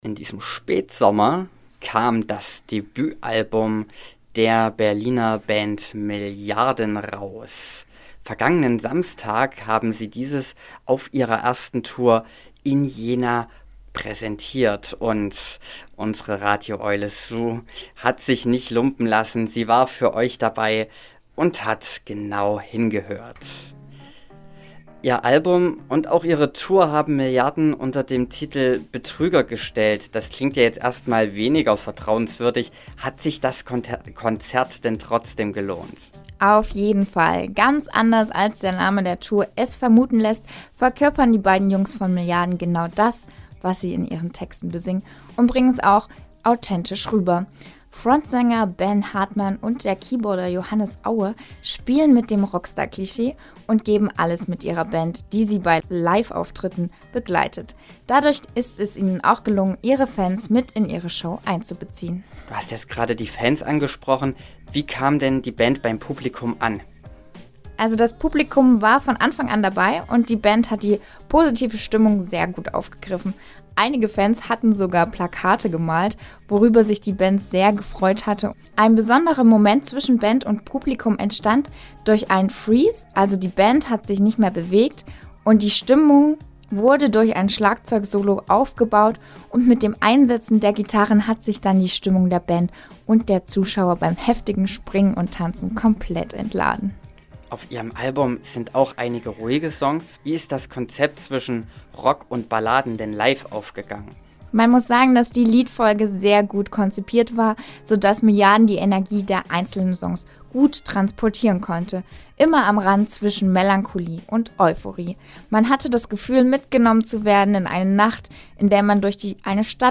Konzertrezension-Milliarden.mp3